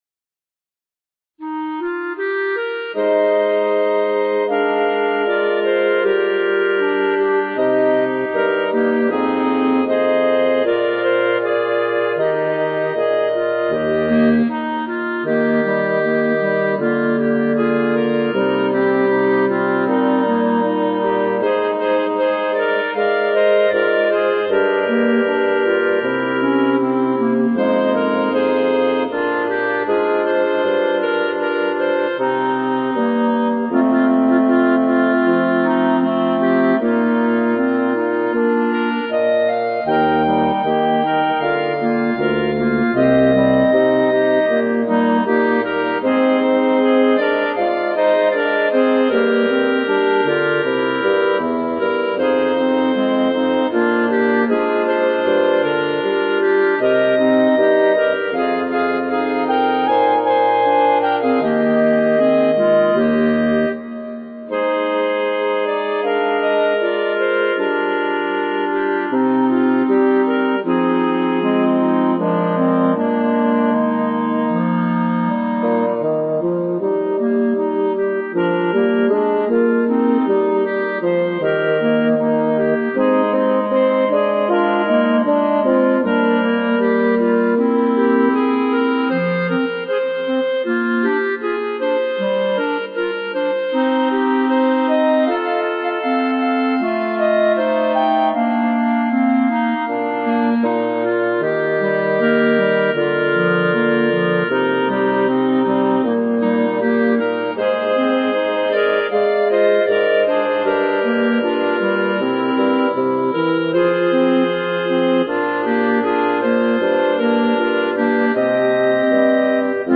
B♭ Clarinet 1 B♭ Clarinet 2 B♭ Clarinet 3 Bass Clarinet
单簧管四重奏
流行